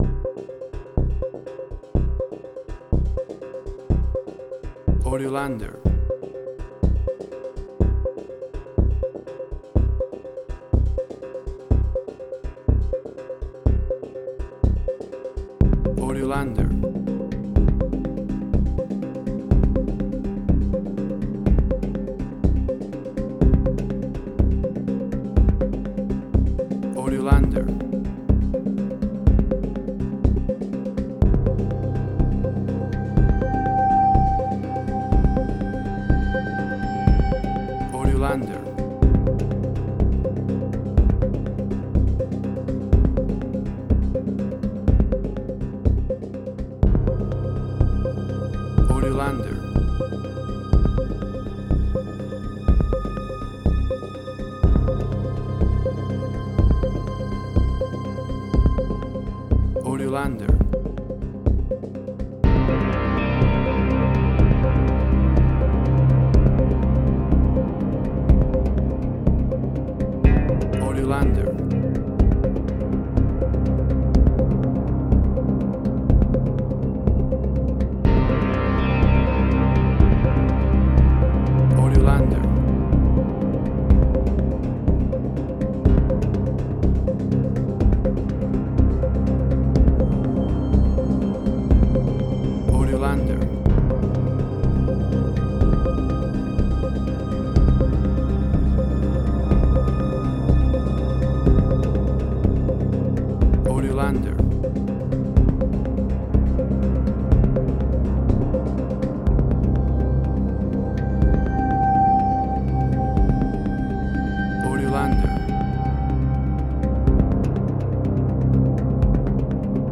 Spaghetti Western, similar Ennio Morricone y Marco Beltrami.
Tempo (BPM): 123